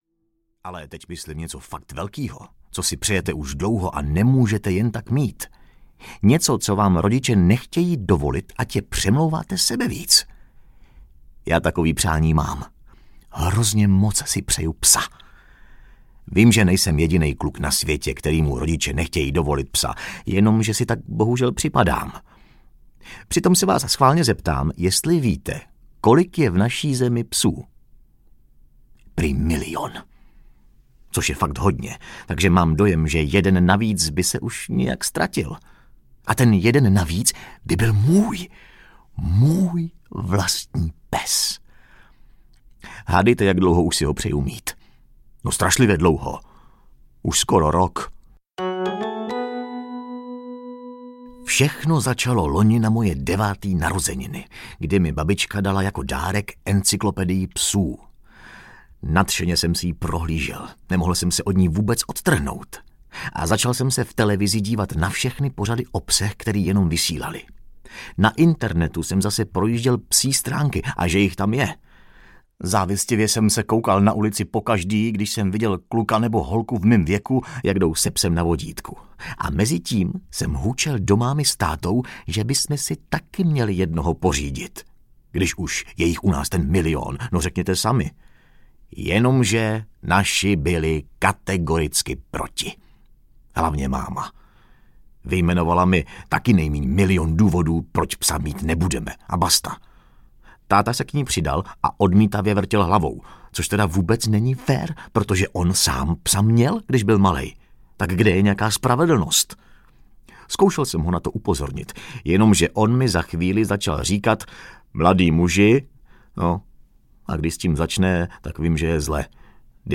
Projekt pes (ten můj) audiokniha
Ukázka z knihy